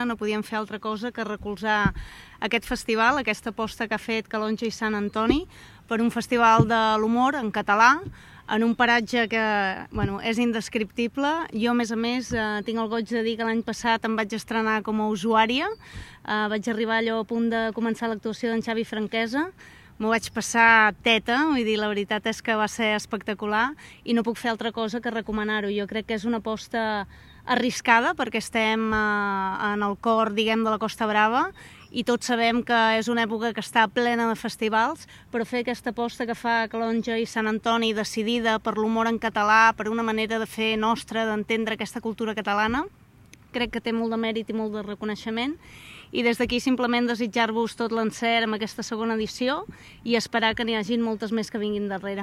També la diputada de la Diputació de Girona, Vanessa Peiró, ha destacat que “reivindicar el dret a riure en català és una aposta per la cultura i la llengua”.